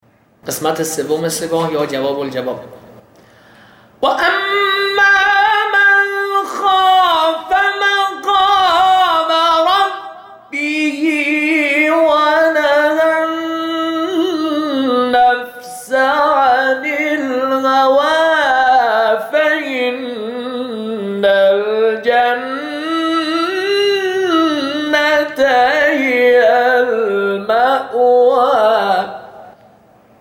آموزش نغمات قرآنی :: باشگاه فرهنگی ورزشی اتحاد خراسان رضوی
22 ـ سه گاه 3 (جواب الجواب )
22سه-گاه3.mp3